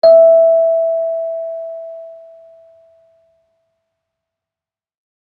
kalimba1_circleskin-E4-mf.wav